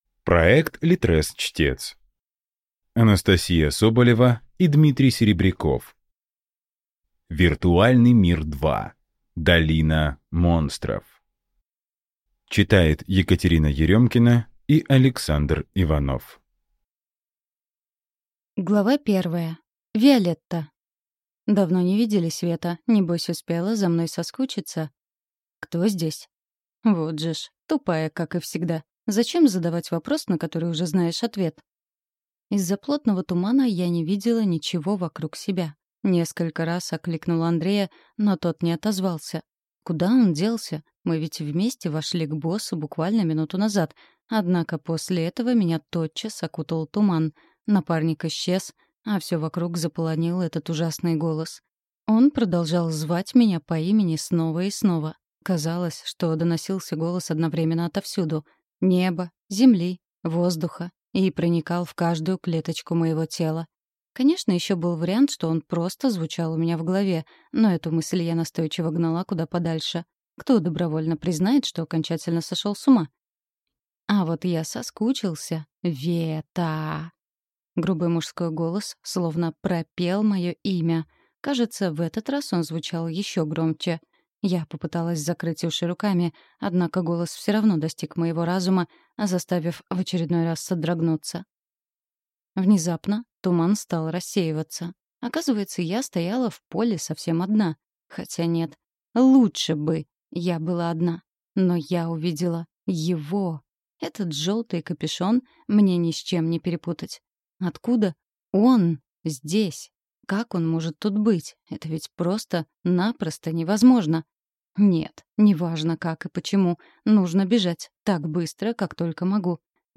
Аудиокнига Виртуальный мир 2. Долина монстров | Библиотека аудиокниг